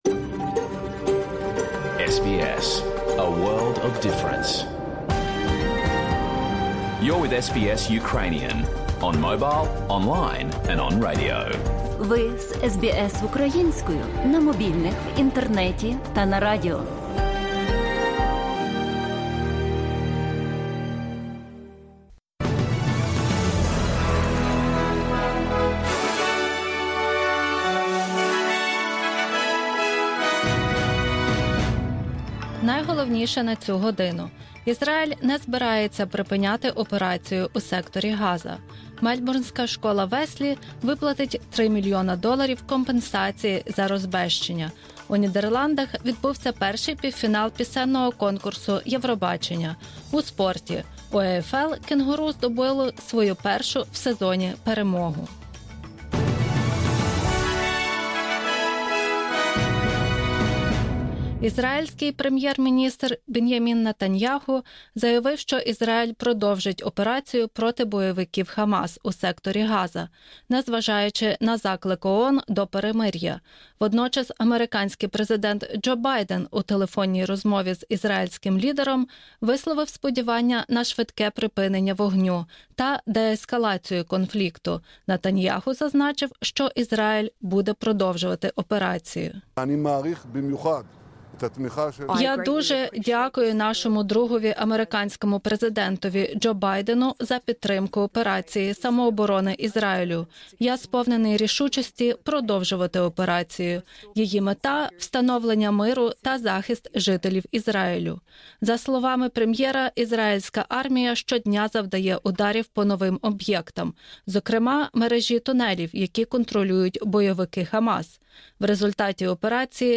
Огляд основних новин Австралії та світу за 20 травня 2021 року.